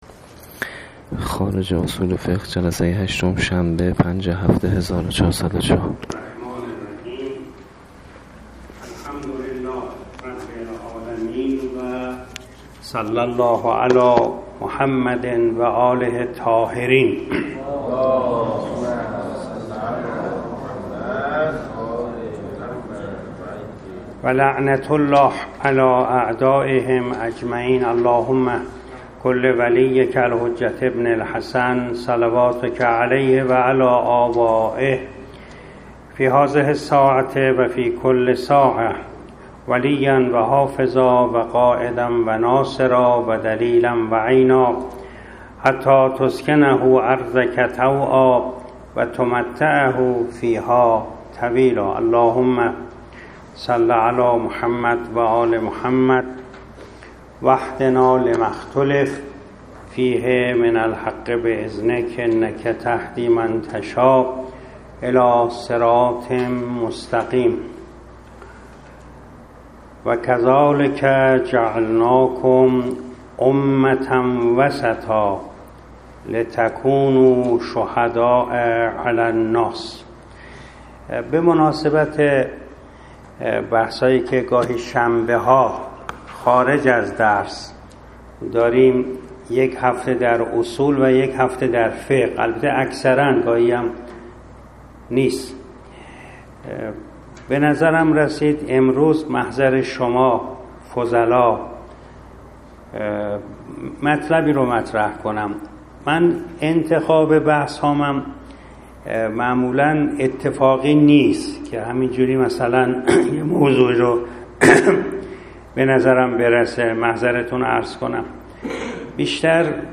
درس اخلاق - نهاد اعتدال و پرهیز از افراط و تفریط در اسلام و کذلک جعلناکم امة وسطاً - غلو و تقصیر بلیّه زندگی دین و دنیوی همه انسان ها - بلیه ای فراگیر - یک مسلمان چند هزار مرتبه در نماز می‌گوید: اهدنا الصراط المستقیم - ویژگی صراط مستقیم اعتدال است.